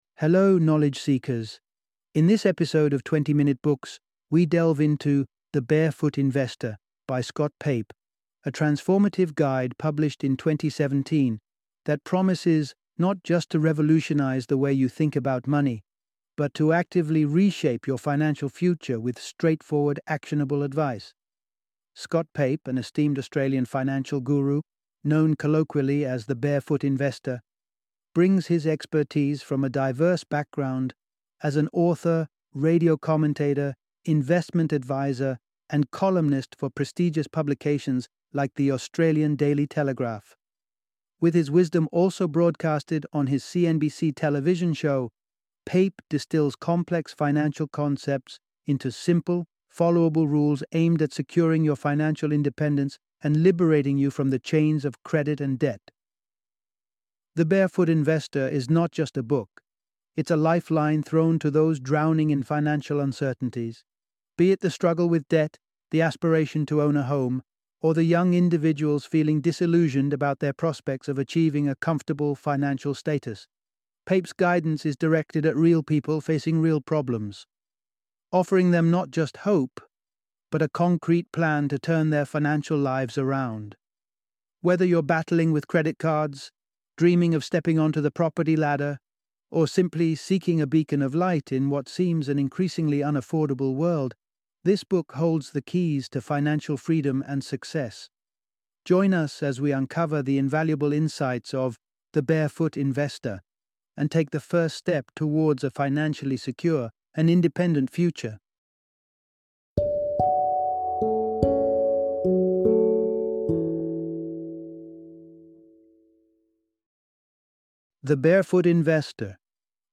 The Barefoot Investor - Audiobook Summary